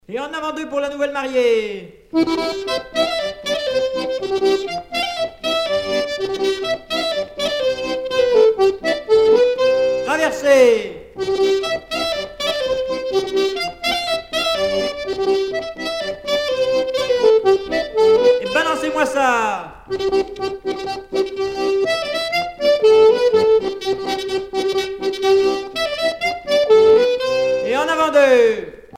danse : branle : avant-deux
Sonneurs de clarinette